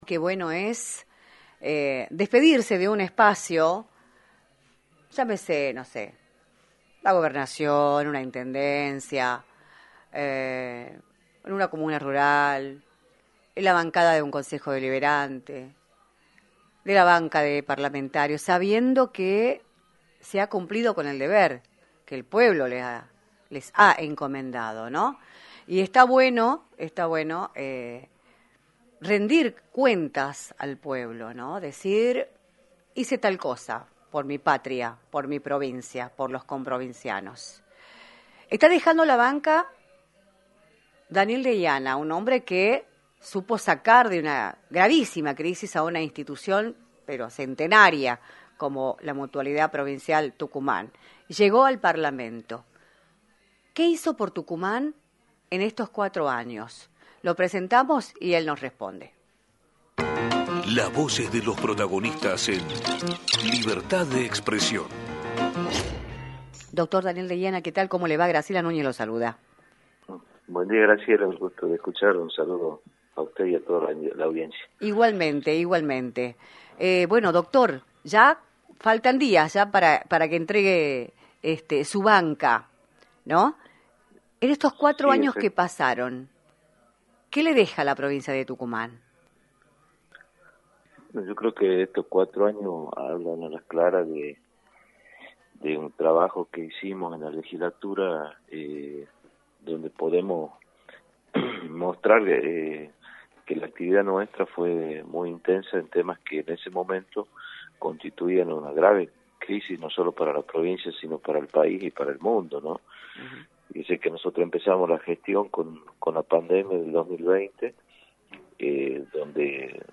Daniel Deiana, Legislador por el oficialismo provincial, analizó en “Libertad de Expresión”, por la 106.9, los resultados de su gestión a lo largo de los últimos 4 años en la Legislatura, afirmó que durante la pandemia trabajó para cuidar la vida y la salud de los tucumanos y remarcó cuáles son sus expectativas para las elecciones del próximo domingo.
entrevista